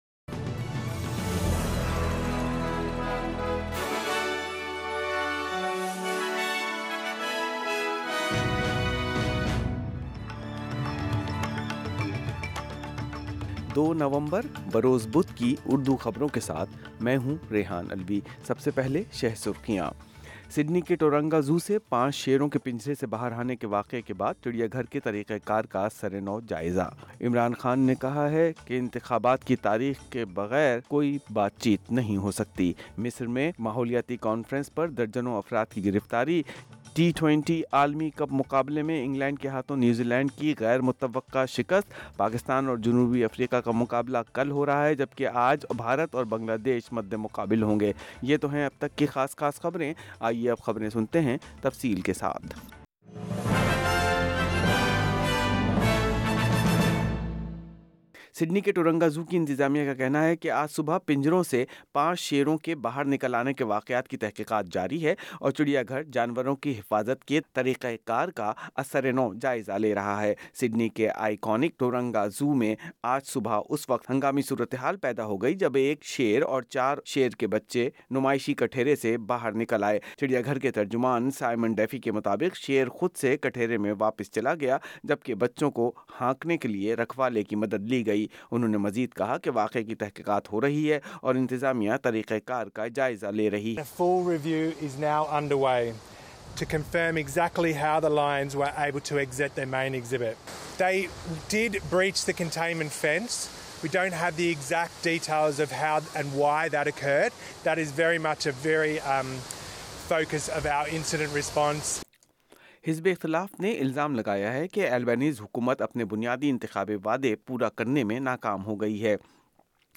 Full News bulletin in Urdu. The government has agreed to meet business groups halfway on some of the more contentious parts of its industrial relations bill.